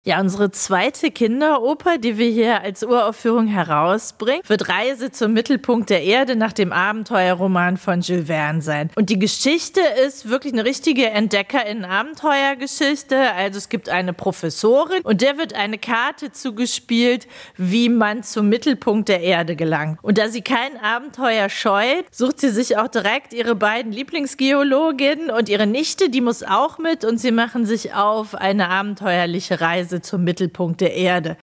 Das Theater Hagen bringt erneut eine Kinderoper zur Uraufführung, die von Kindern für Kinder gesungen, musiziert und gespielt wird.